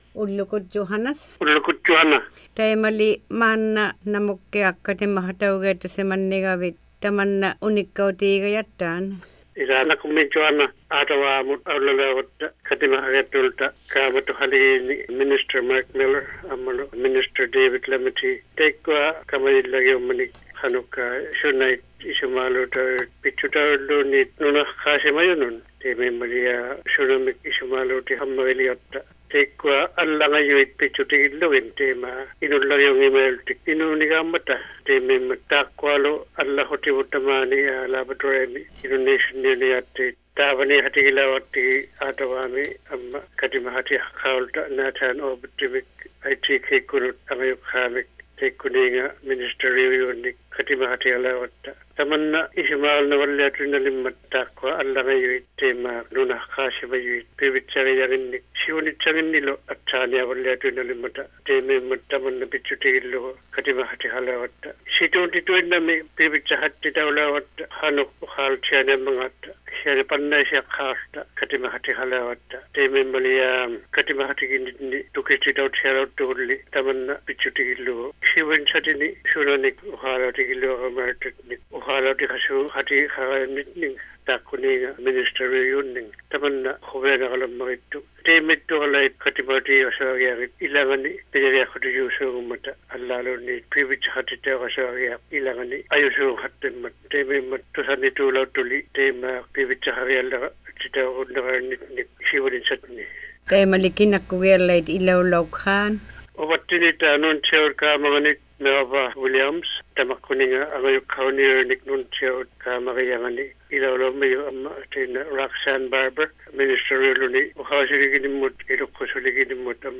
Click here to hear Lampe give you more information about the meeting.